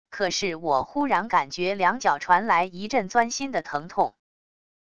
可是我忽然感觉两脚传来一阵钻心的疼痛wav音频生成系统WAV Audio Player